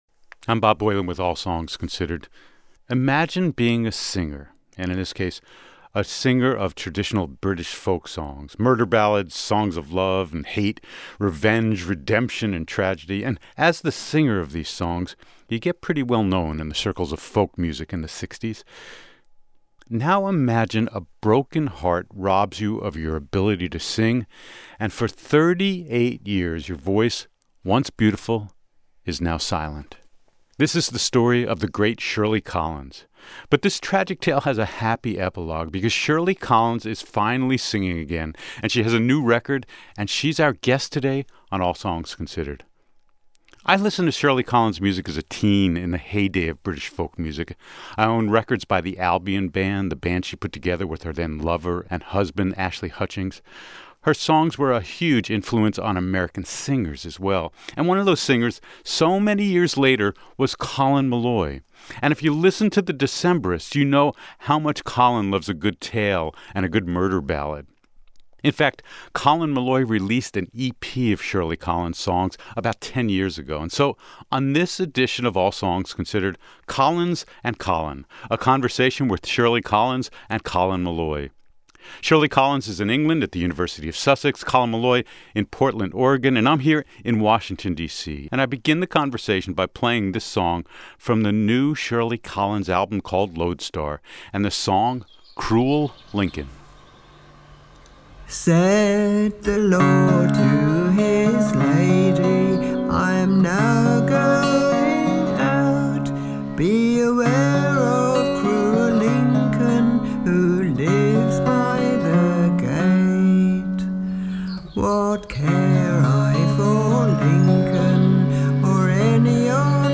Shirley Collins was a huge part of the British folk scene — but for a time, heartbreak silenced her voice. We talk with her and The Decemberists' Colin Meloy, who's captivated by Collins' music.